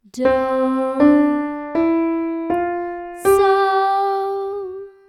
The piano will be playing the whole scale, and the voice will emphasize the tonic and the dominant.
The first five steps of a diatonic major scale
Hearing-Harmonies-1-Ex-3-The-first-five-steps-of-a-major-diatonic-scale-with-emphasised-tonic-and-dominant.mp3